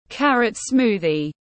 Sinh tố cà rốt tiếng anh gọi là carrot smoothie, phiên âm tiếng anh đọc là /ˈkær.ət ˈsmuː.ði/
Carrot smoothie /ˈkær.ət ˈsmuː.ði/